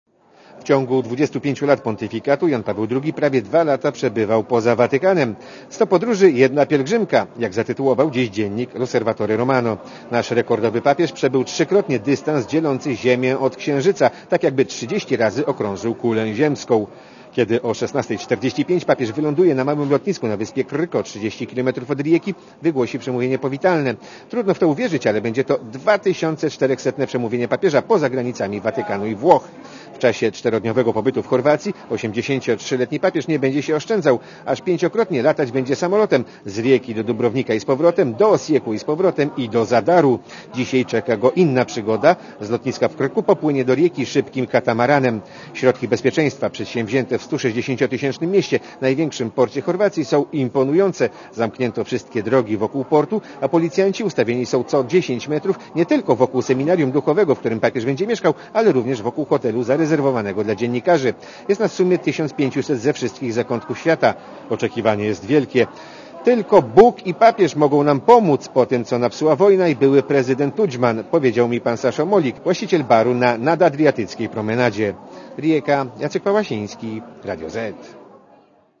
(RadioZet) Źródło: (RadioZet) Komentarz audio (310Kb) Pielgrzymka do Chorwacji będzie 100. podróżą papieża poza granice Włoch.